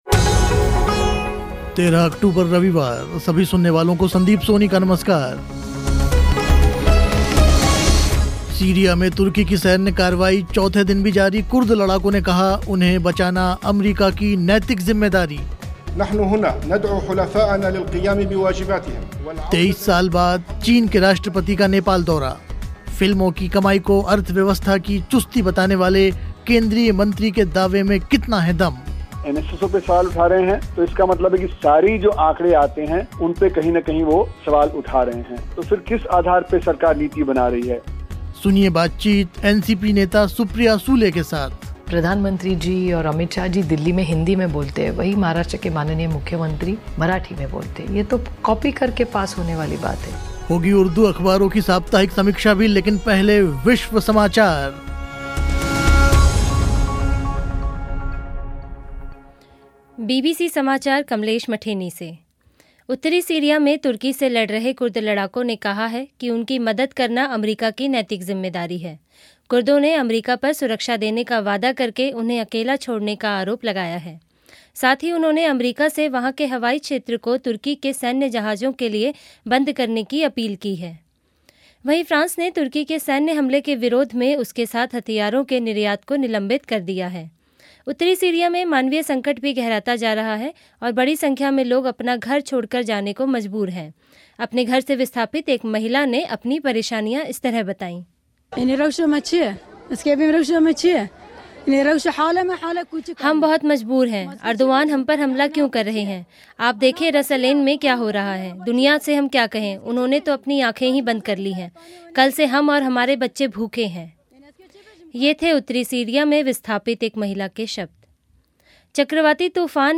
सुनिए बातचीत एनसीपी नेता सुप्रिया सूले के साथ. मिलिए बॉक्सर मैरी कॉम से. होगी उर्दू अख़बारों की साप्ताहिक समीक्षा भी.